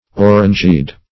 Search Result for " orangeade" : Wordnet 3.0 NOUN (1) 1. sweetened beverage of diluted orange juice ; The Collaborative International Dictionary of English v.0.48: Orangeade \Or`ange*ade"\, n. [F., fr. orange.] A drink made of orange juice and water, corresponding to lemonade ; orange sherbet.